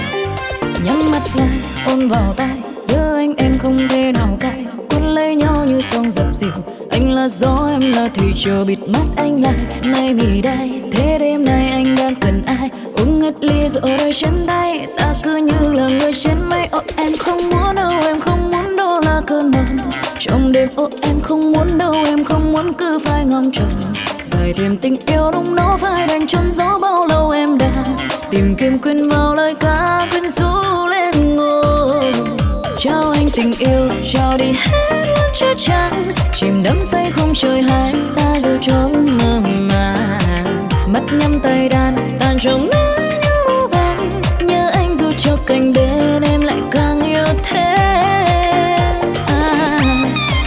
Nhạc Trẻ.